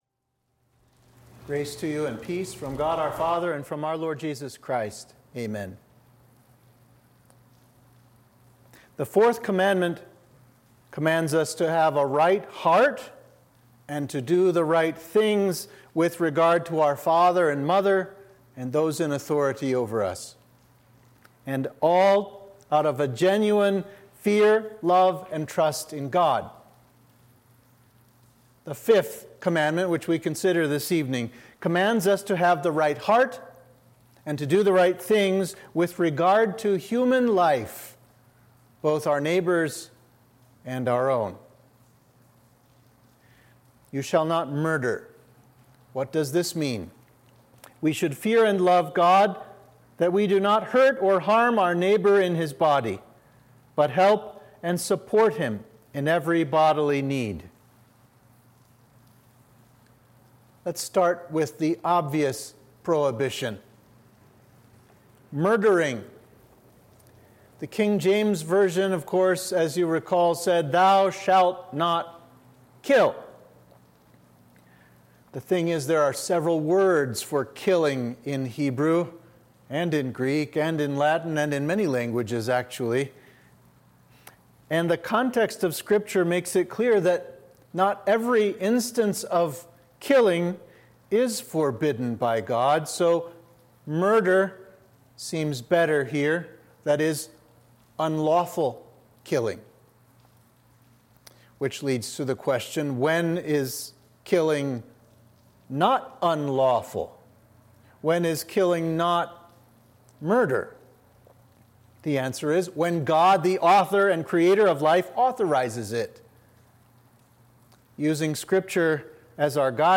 Sermon for Midweek of Trinity 10